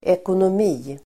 Uttal: [ekonom'i:]